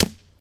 default_sword_wood2.ogg